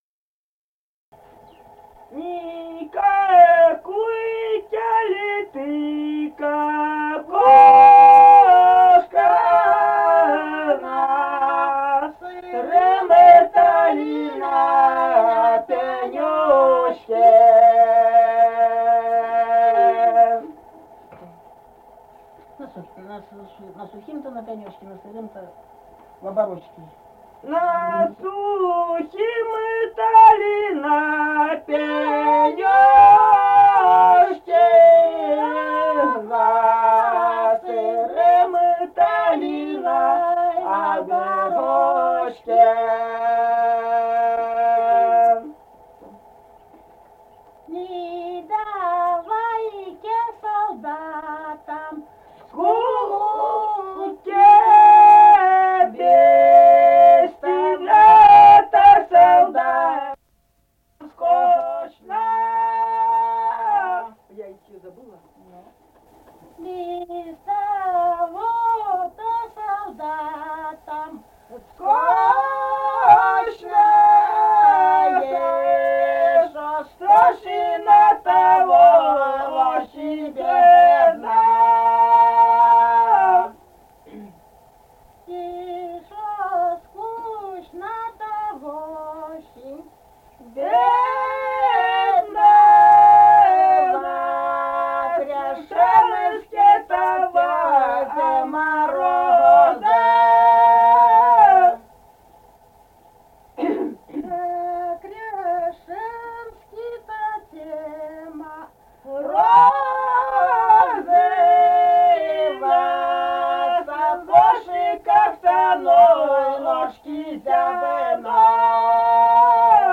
Республика Казахстан, Восточно-Казахстанская обл., Катон-Карагайский р-н, с. Язовая, июль 1978.